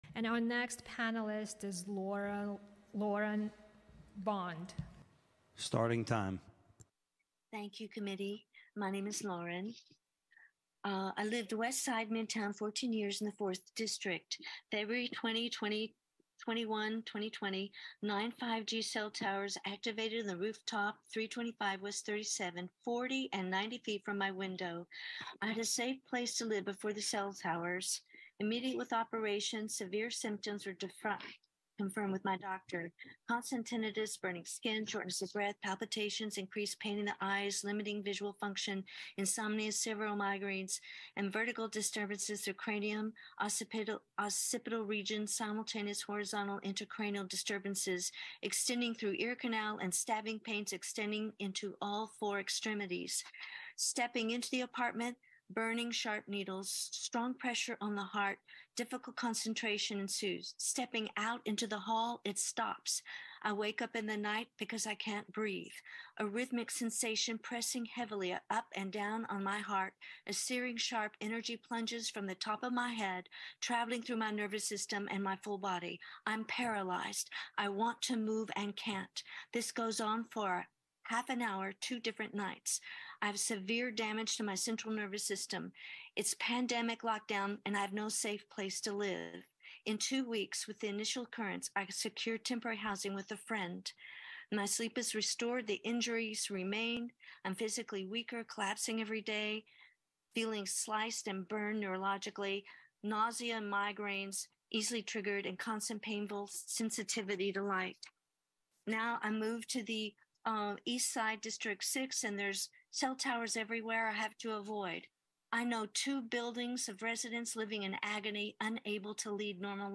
New York City Council Hearing on 5G Jumbo Towers Part 6